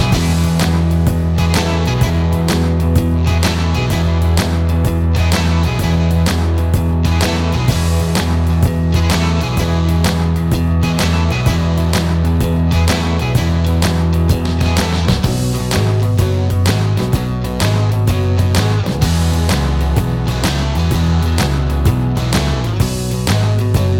no Backing Vocals Medleys 3:55 Buy £1.50